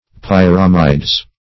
Pyramides - definition of Pyramides - synonyms, pronunciation, spelling from Free Dictionary
Pyramides - definition of Pyramides - synonyms, pronunciation, spelling from Free Dictionary Search Result for " pyramides" : The Collaborative International Dictionary of English v.0.48: Pyramis \Pyr"a*mis\, n.; pl. Pyramides .